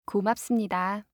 알림음(효과음) + 벨소리
알림음 8_고맙습니다1-여자.mp3